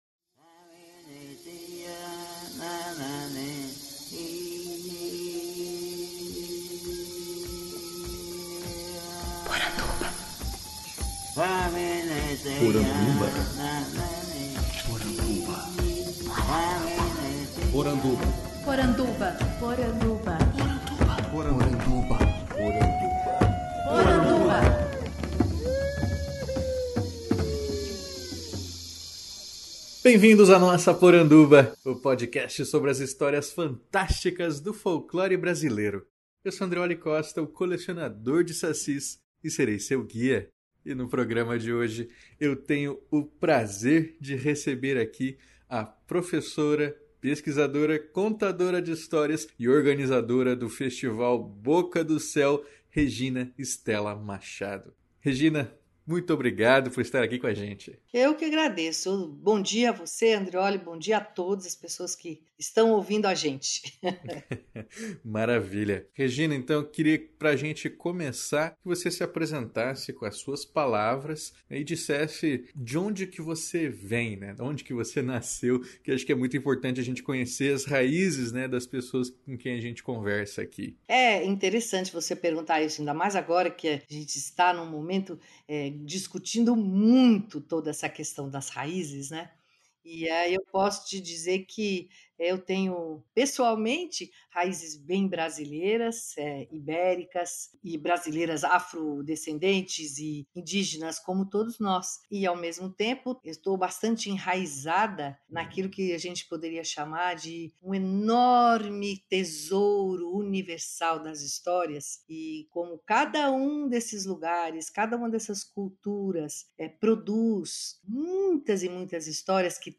Podcast que entrevista